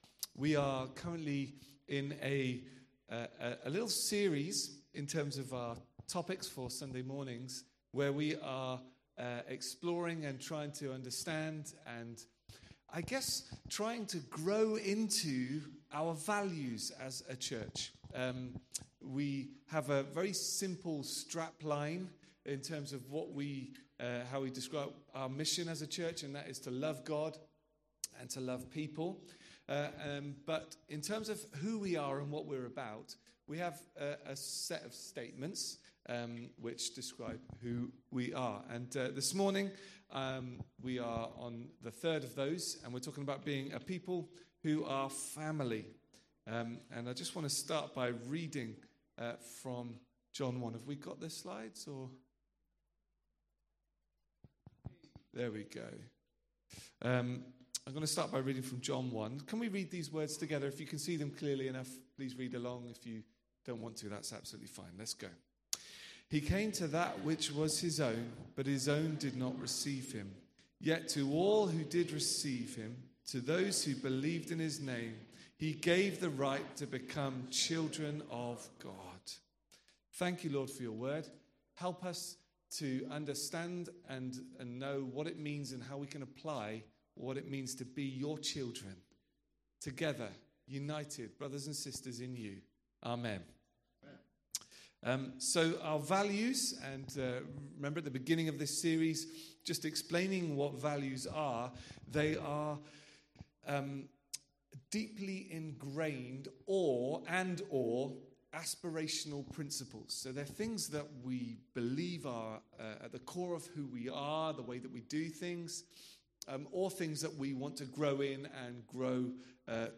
Date: 10/03/2024 Resources: mp3 Sermon Notes Watch on YouTube